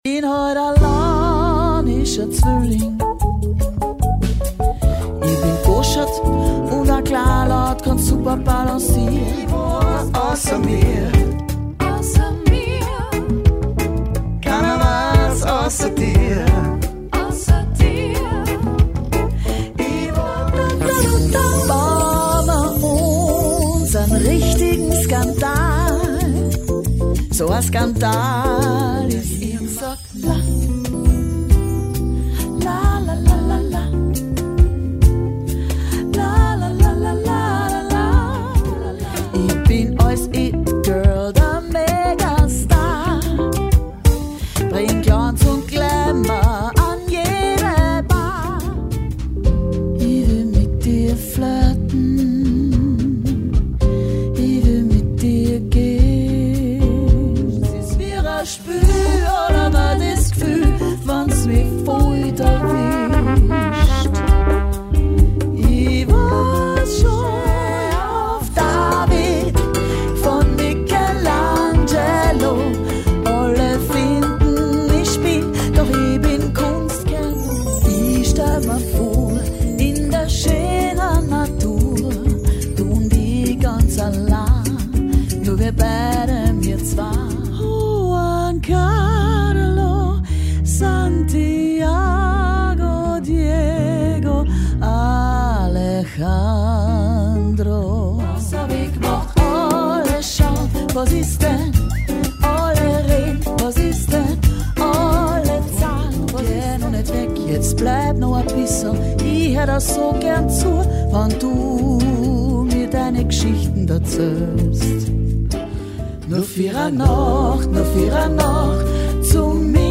südamerikanischen Rhythmen und Melodien